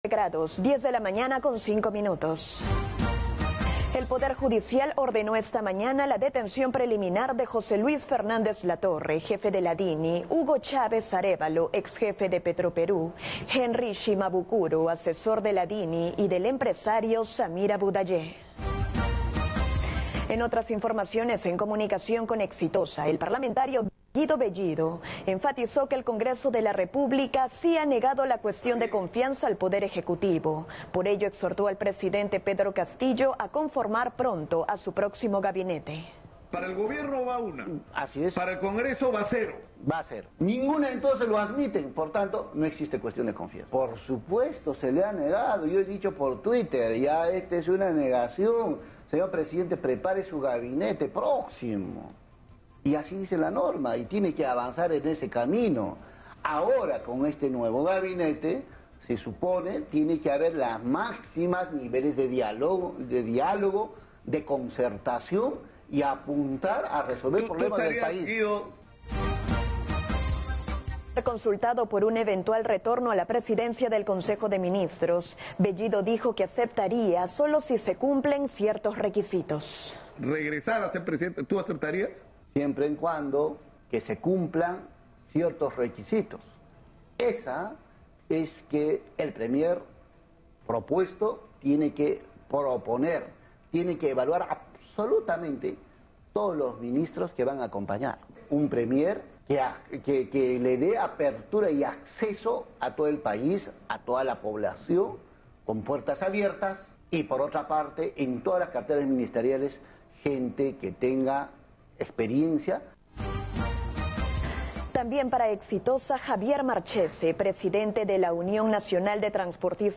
por Radio Exitosa - Lima